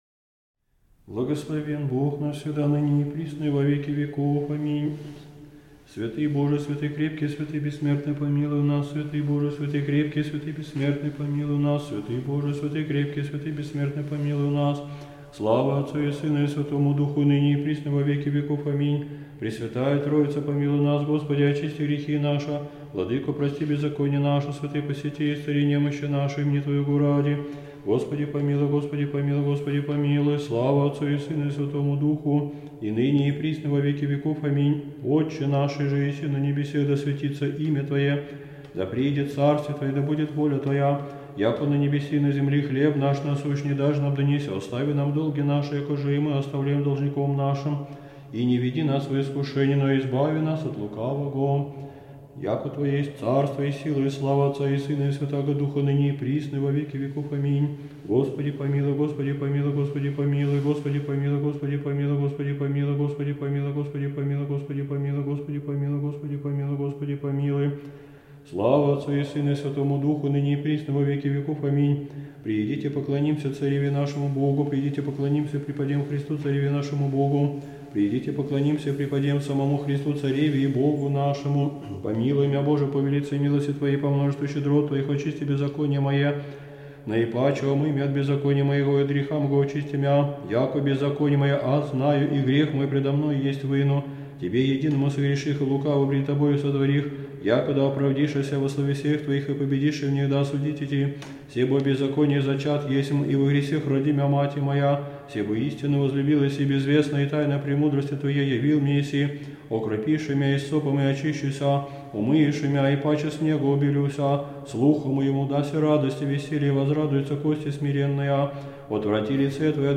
Аудиокнига Канон за болящего | Библиотека аудиокниг